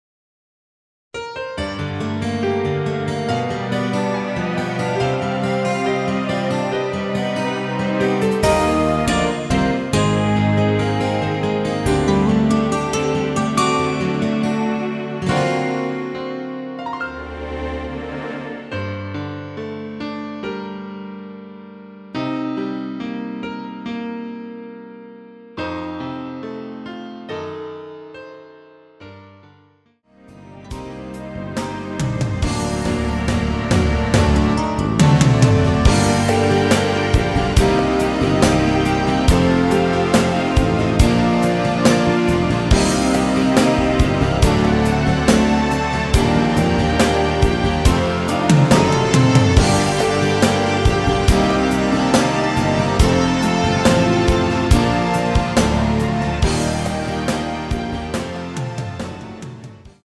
Gm
◈ 곡명 옆 (-1)은 반음 내림, (+1)은 반음 올림 입니다.
앞부분30초, 뒷부분30초씩 편집해서 올려 드리고 있습니다.
중간에 음이 끈어지고 다시 나오는 이유는